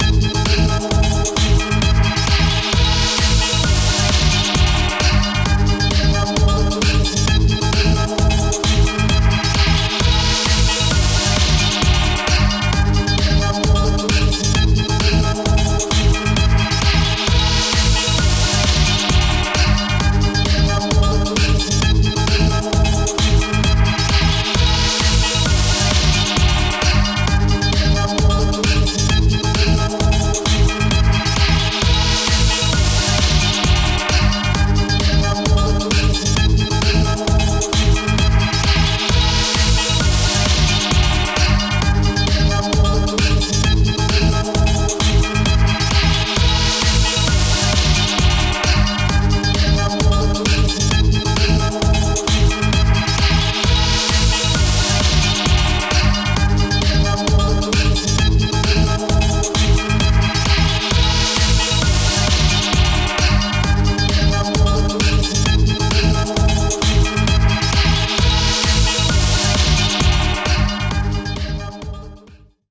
Type BGM
Speed 110%